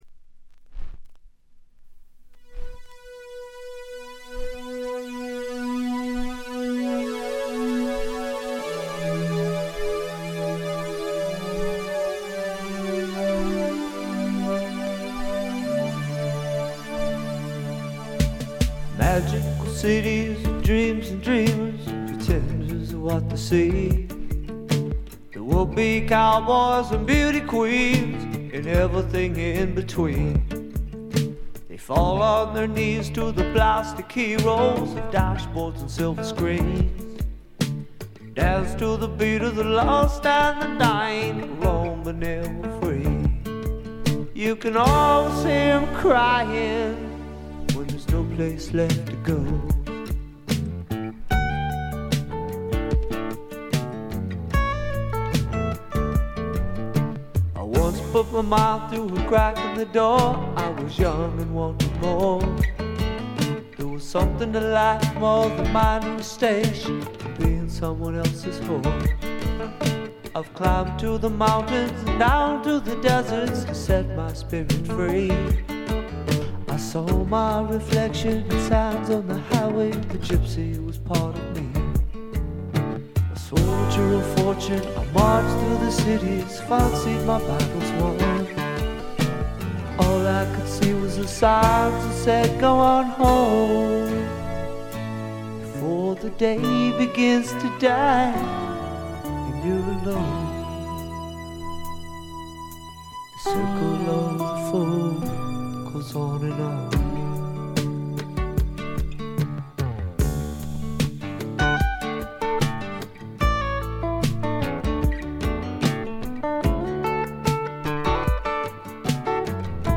ごくわずかなノイズ感のみ。
シンプルなギター・ポップと言ったおもむきでばっちりハマる人もいそうなサウンドです。
試聴曲は現品からの取り込み音源です。
Lead Guitar, Rhythm Guitar, Vocals
Bass, Vocals
Drums